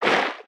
Sfx_creature_trivalve_swim_fast_06.ogg